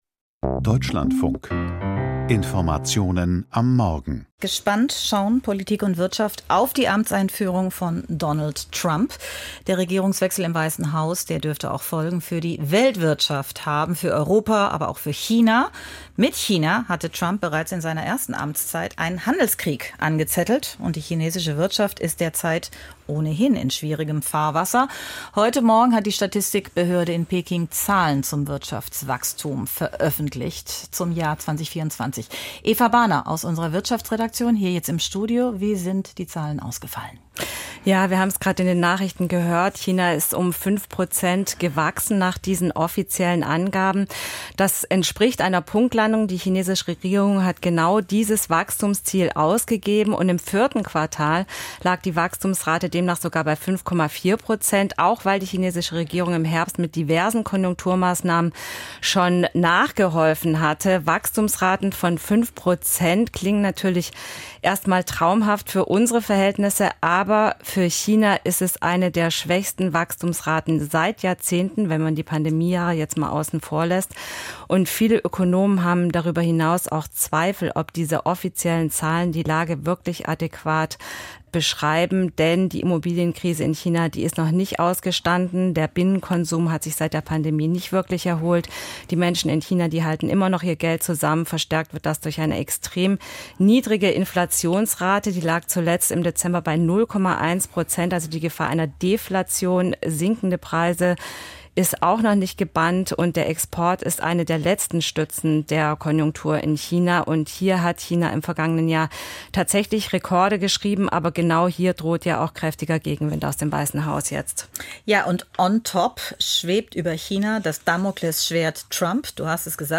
Wirtschaftsgespräch: Chinas Wirtschaftswachstum